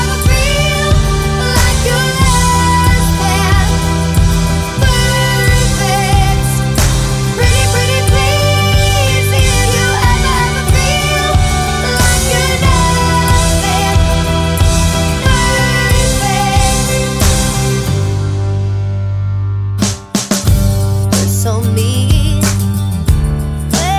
With Swearing Cut Rock 3:34 Buy £1.50